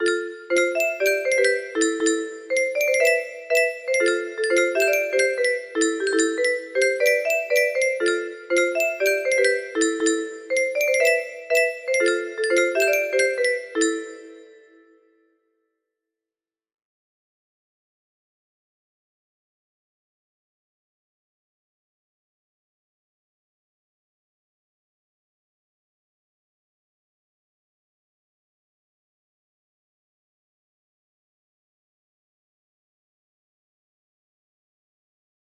15 note version